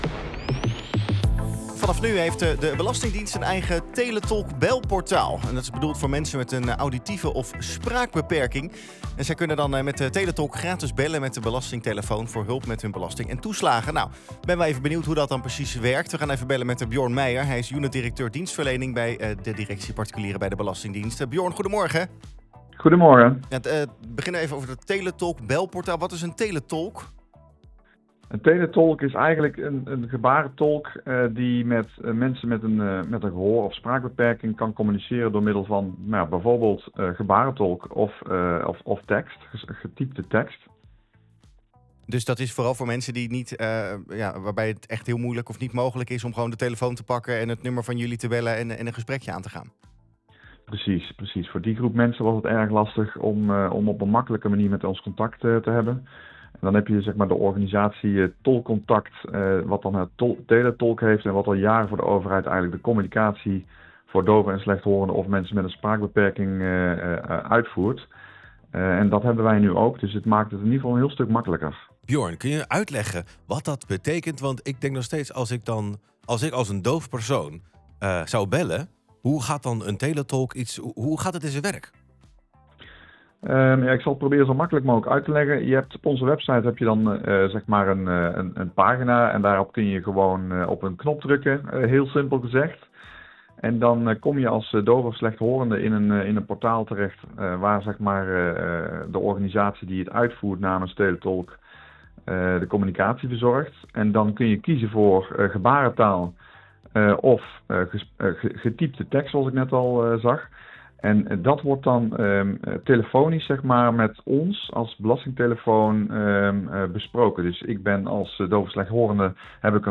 in de Centraal+ Ochtendshow